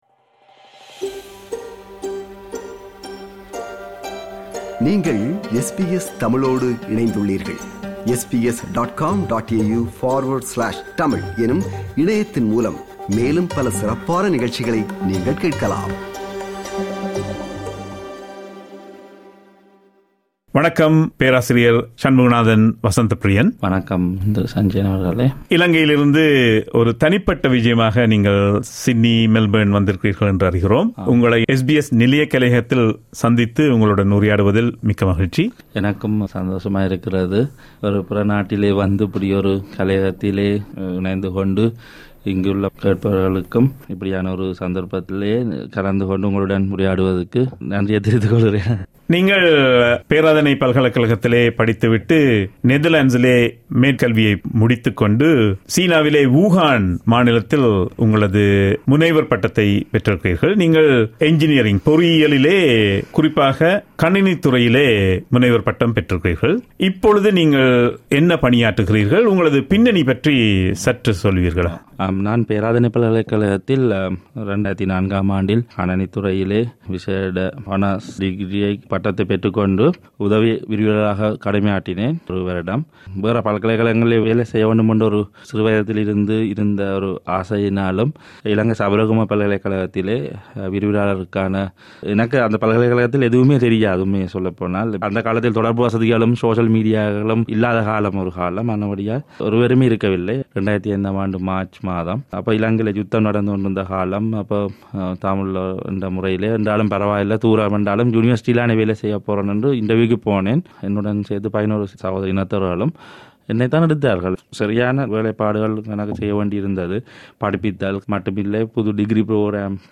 at the SBS studios in Sydney